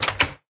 door_open.ogg